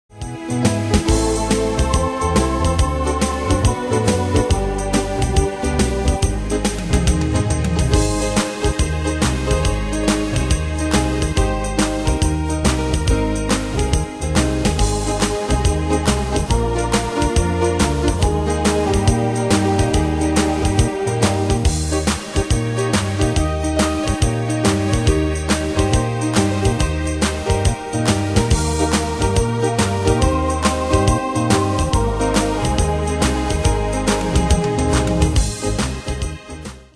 Style: PopAnglo Ane/Year: 1994 Tempo: 140 Durée/Time: 2.09
Danse/Dance: TripleSwing Cat Id.
Pro Backing Tracks